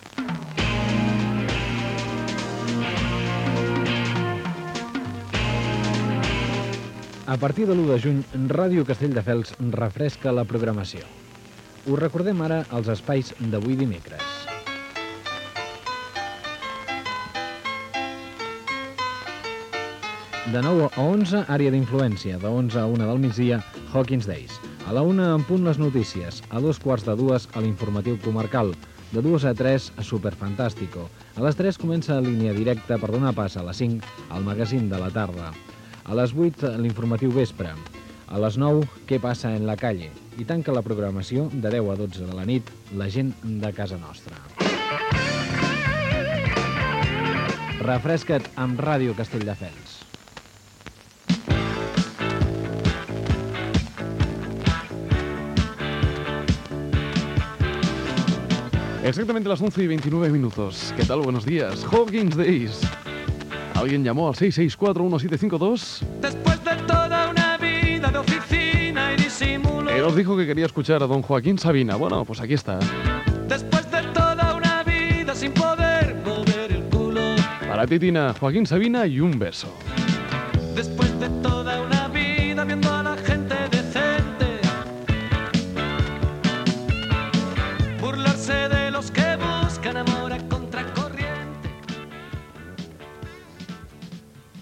Programació de l'emissora i presentació d'un tema musical
FM